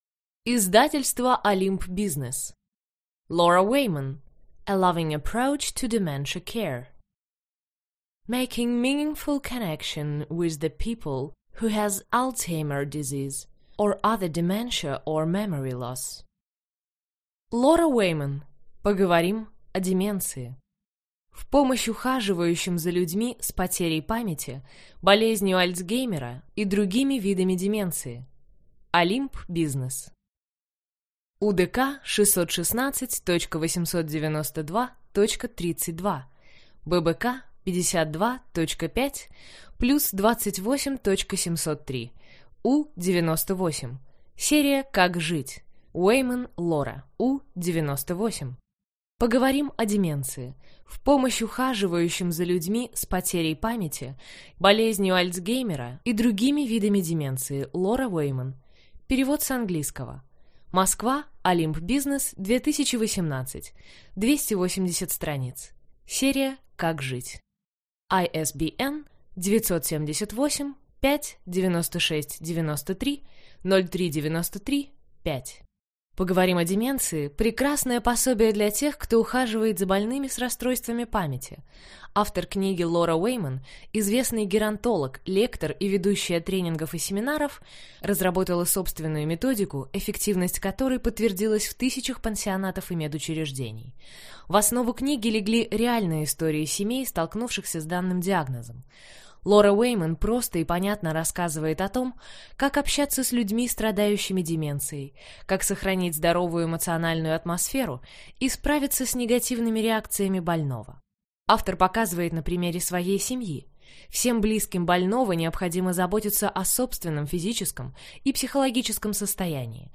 Аудиокнига Поговорим о деменции. В помощь ухаживающим за людьми с потерей памяти, болезнью Альцгеймера и другими видами деменции | Библиотека аудиокниг